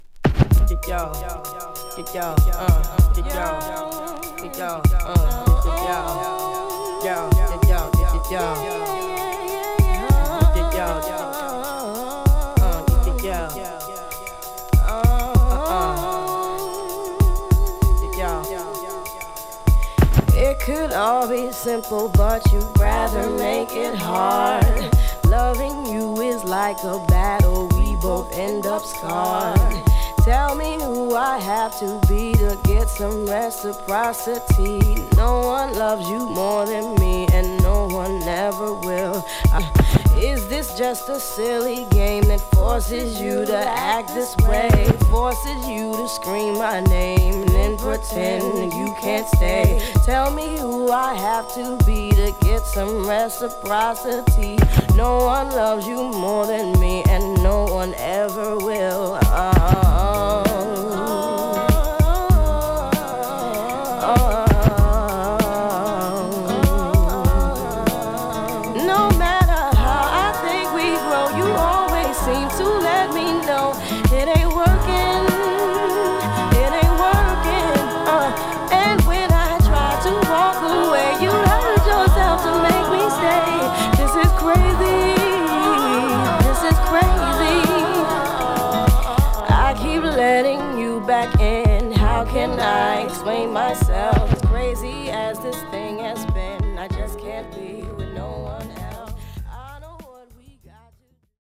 軽やかなストリングストラックに彼女のスムースな歌声が合わさる好曲!　90年代のクールでもありメロウでもある良きR&B!!